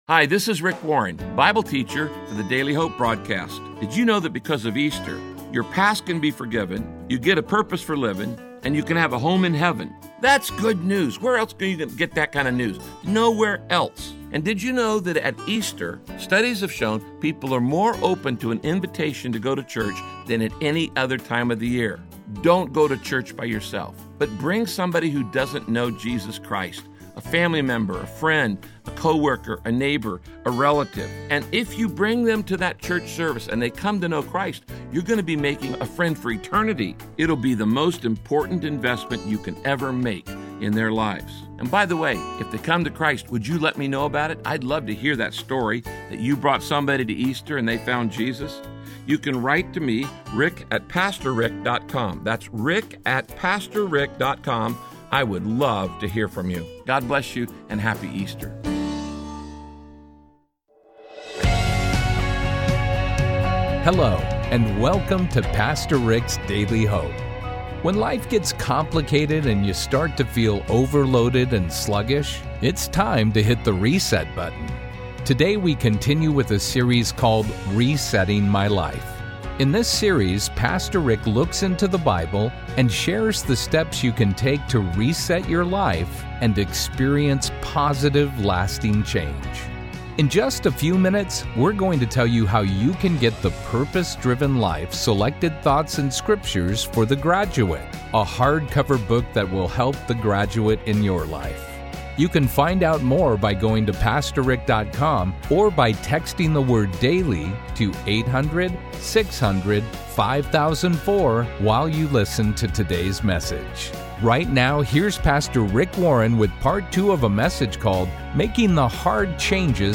Pastor Rick teaches in this message how the Holy Spirit helps you overcome destructive habits as God transforms you into the person he created you to be.